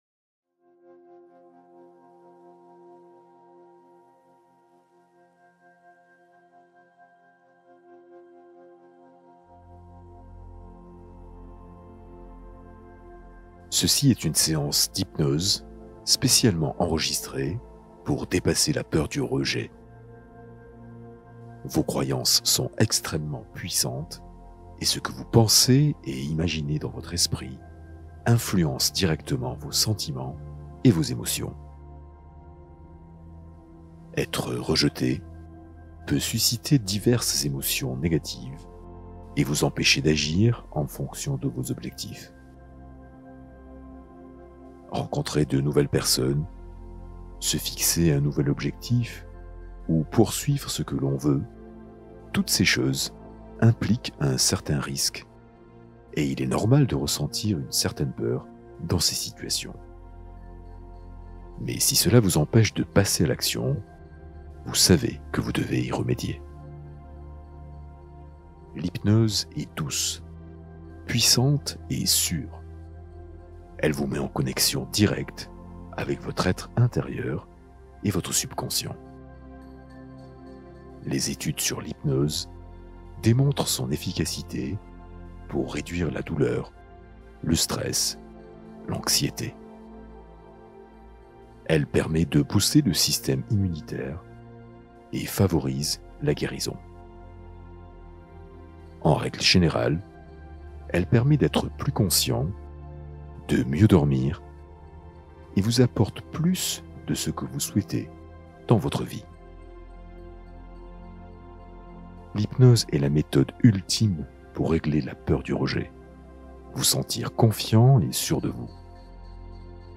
Méditation 777 Hz : approche approfondie de l’état vibratoire